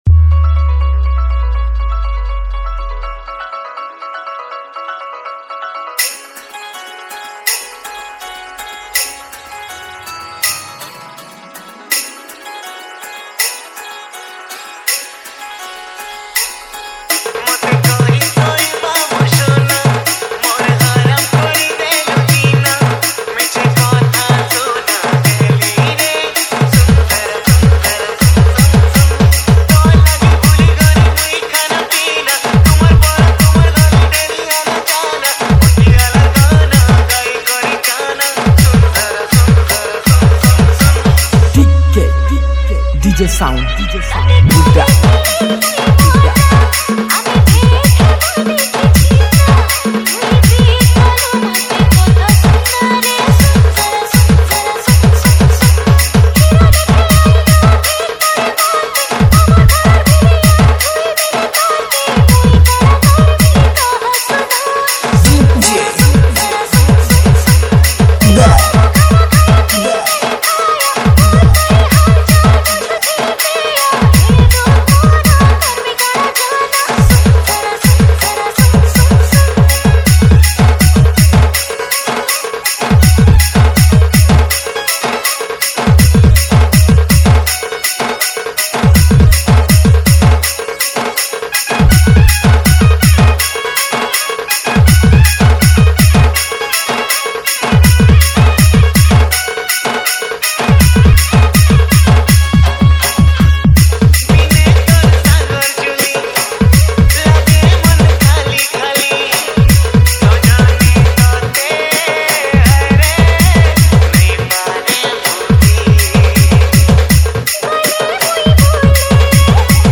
SAMBALPURI SAD DJ REMIX